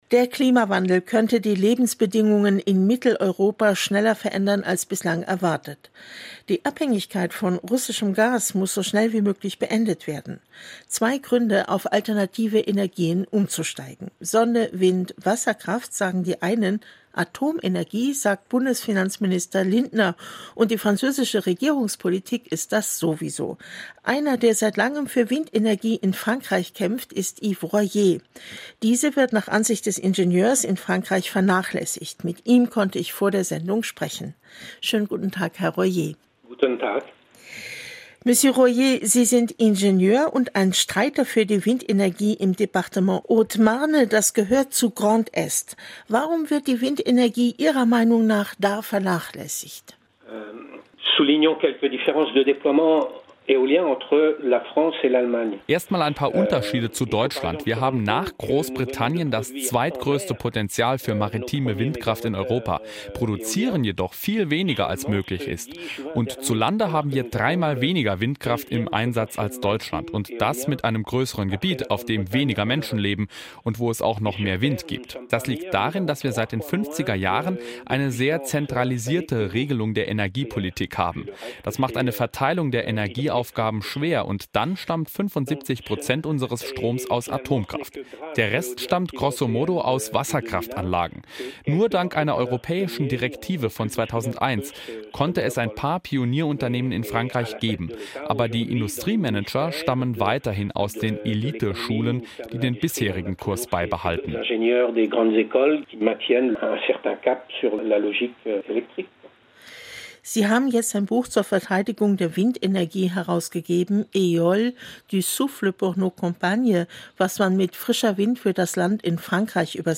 Juillet 2022 – Sur les antennes de la radio du Land de Sarre